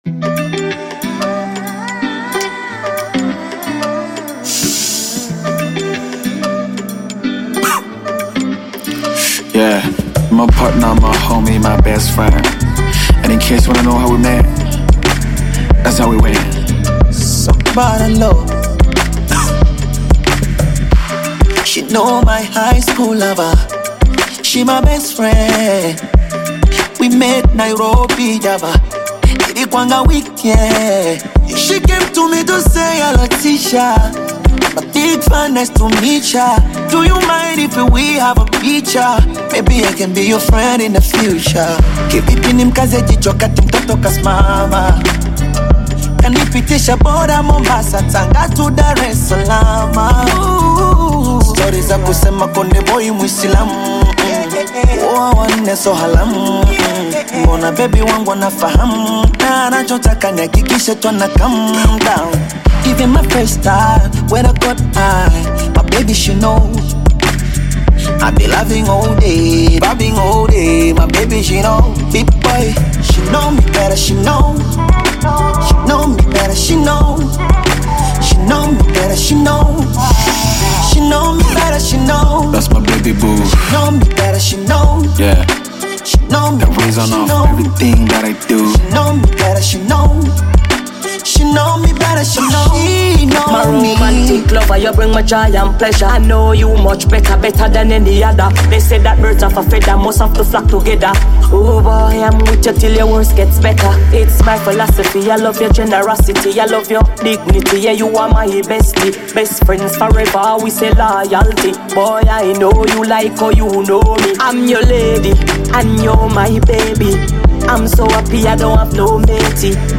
Jamaican dancehall